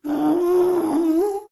moan5.ogg